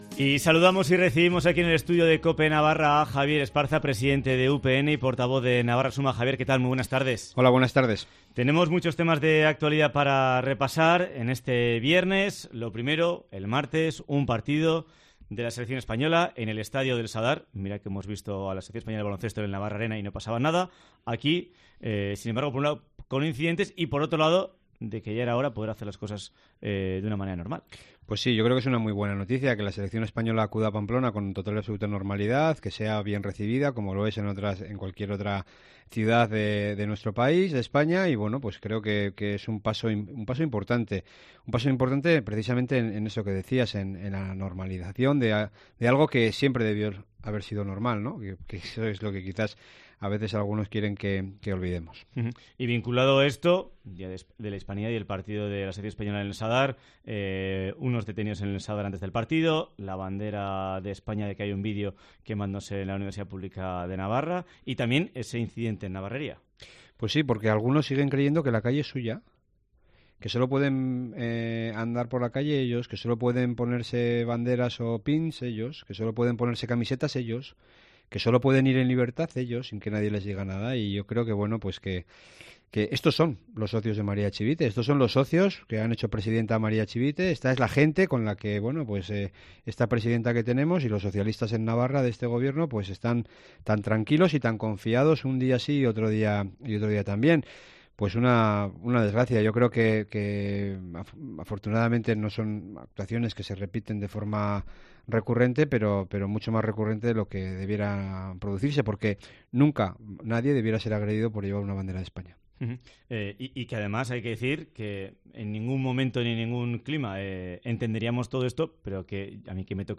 Entrevista a Javier Esparza en COPE Navarra